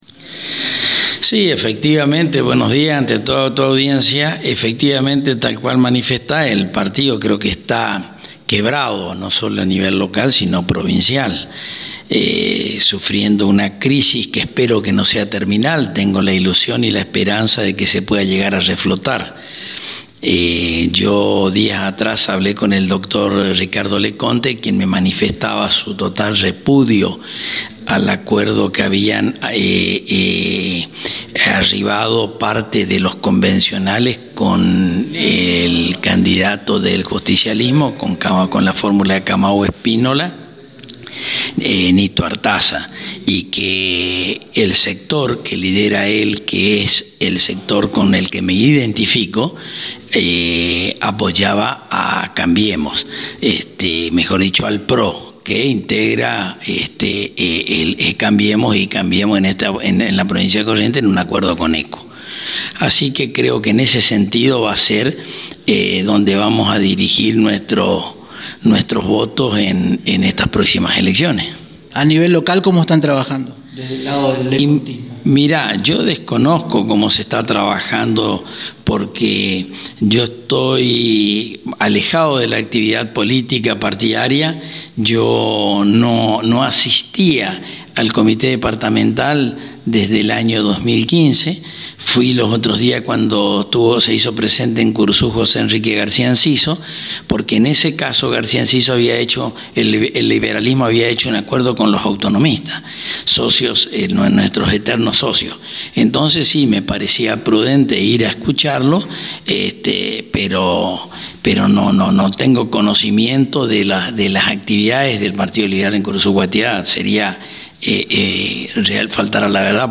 Escuchá la nota ampliada